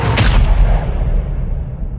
bong.mp3